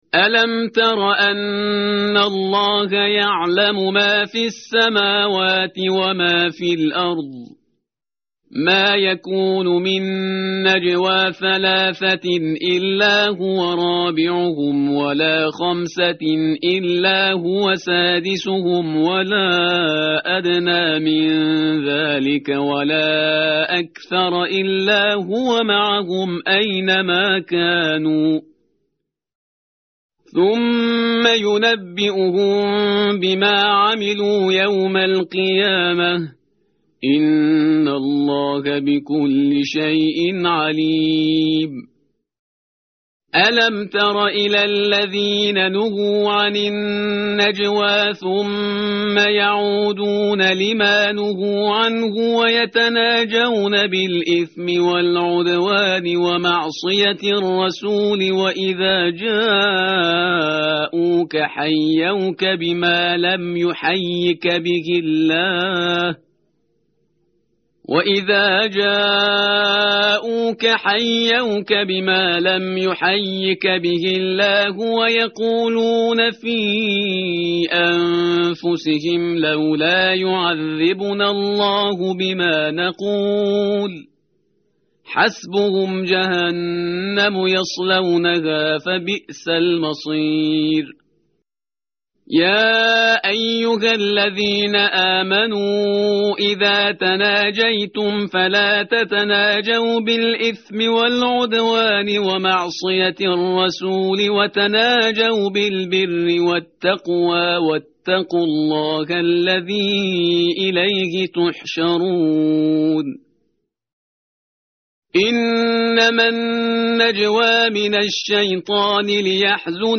متن قرآن همراه باتلاوت قرآن و ترجمه
tartil_parhizgar_page_543.mp3